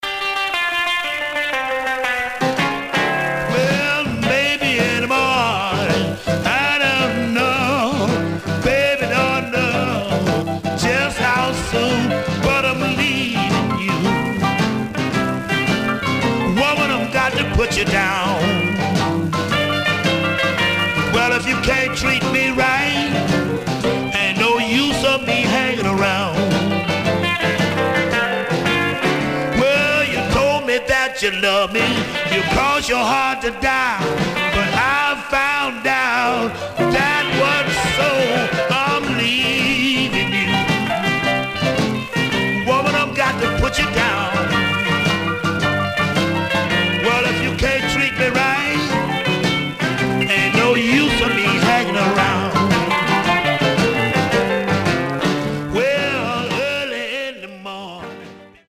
Some surface noise/wear
Stereo/mono Mono
Rythm and Blues